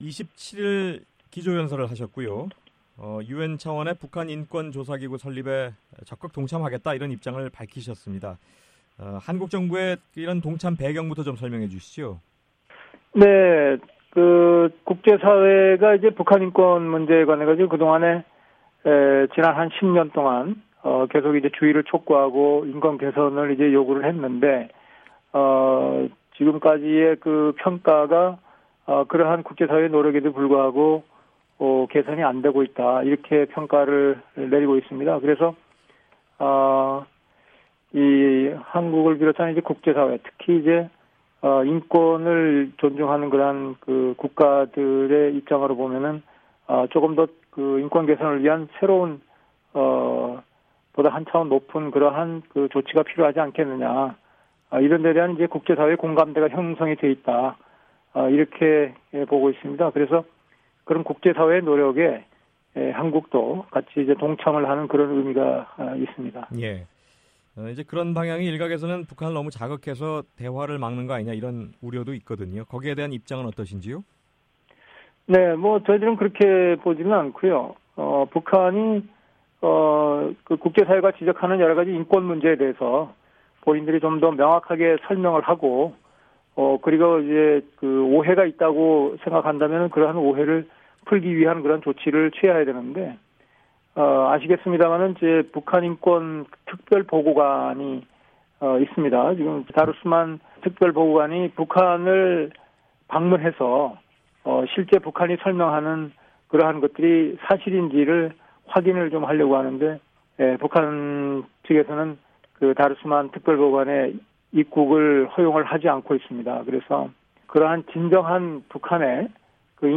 [인터뷰] 김봉현 한국 외교통상부 다자외교조정관